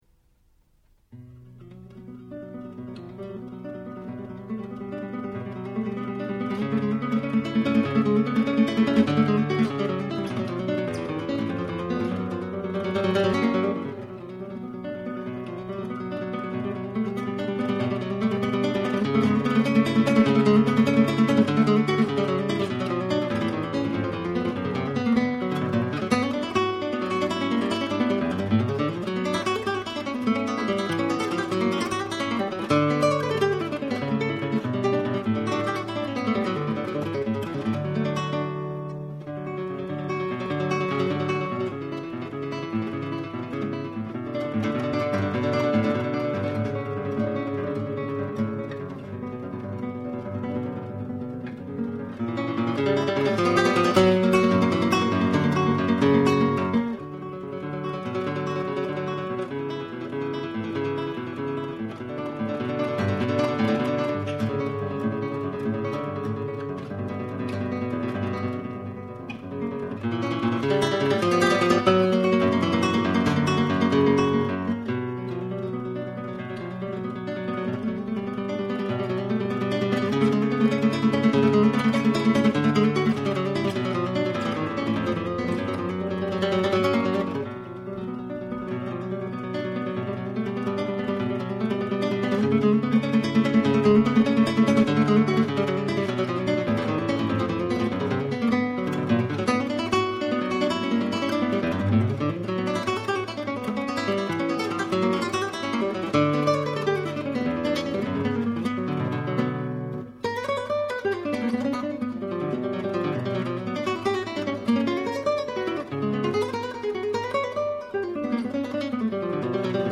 0265-吉他名曲小快板泪水.mp3